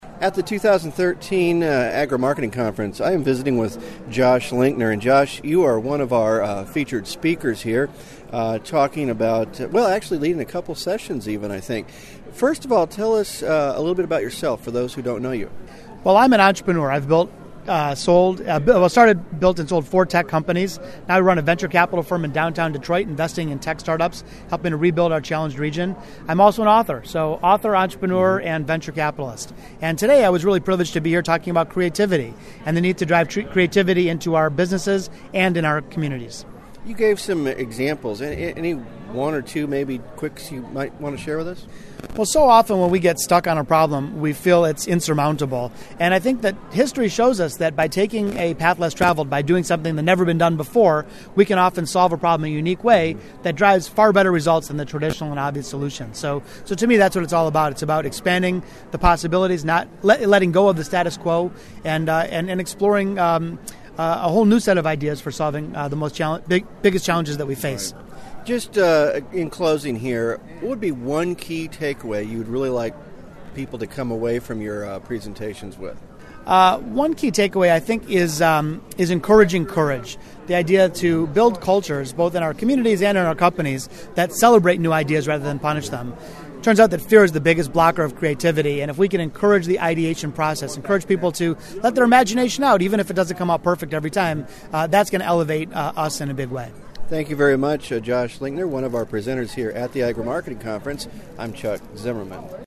2013 Agri-Marketing Conference
Interview